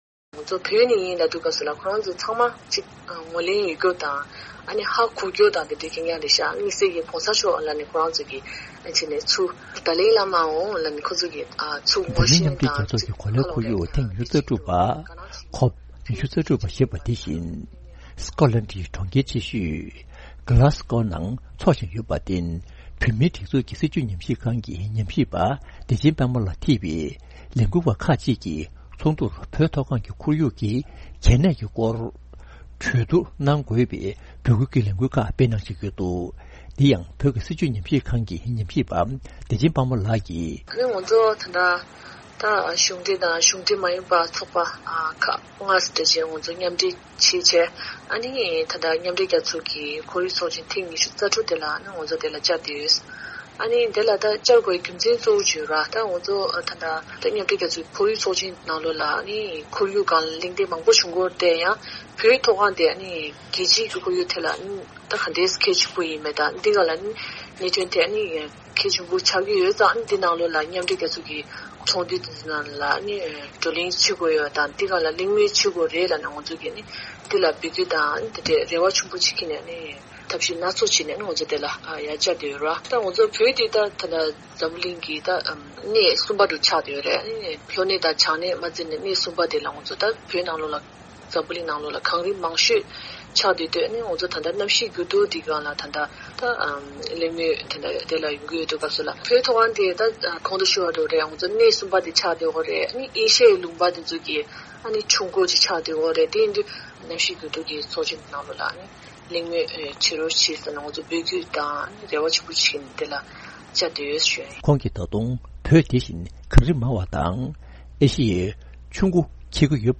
འབྲེལ་ཡོད་མི་སྣར་བཅར་འདྲི་ཞུས་ནས་གནས་ཚུལ་ཕྱོགས་བསྒྲིགས་བྱས་པ་ཞིག་སྙན་སྒྲོན་ཞུ་ཡི་རེད།།